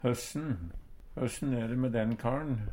høssen - Numedalsmål (en-US)